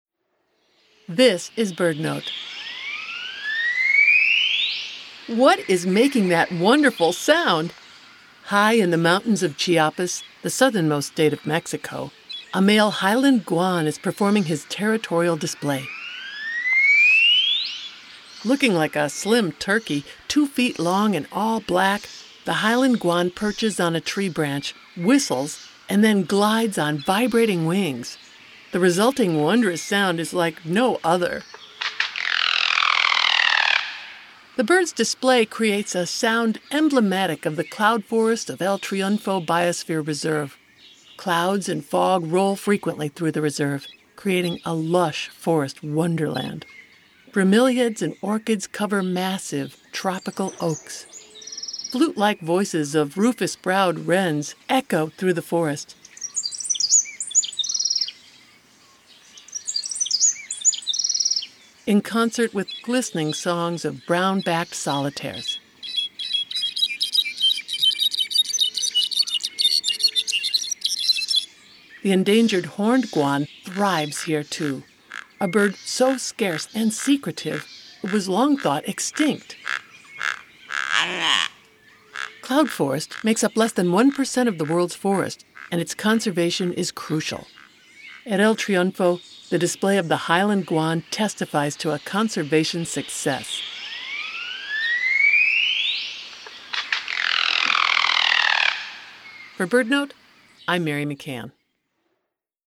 High in the mountains of Chiapas, the southernmost state of Mexico, a male Highland Guan is performing his territorial display. The Highland Guan perches on a tree-branch, whistles, and then glides on vibrating wings. The resulting wondrous sound is like no other. The bird’s display creates a sound emblematic of the cloud forest of El Triunfo Biosphere Reserve.